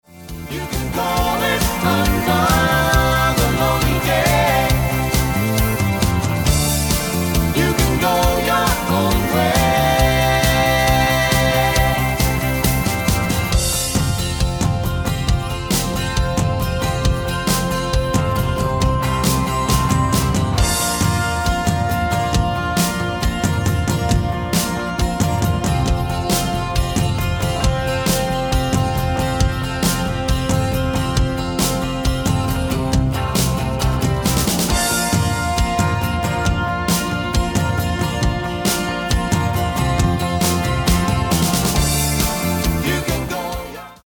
MP3-orkestband gearrangeerd in de stijl van:
Genre: Evergreens & oldies
Toonsoort: F
Ook verkrijgbaar met backingvocals!
File type: 44.1KHz, 16bit Stereo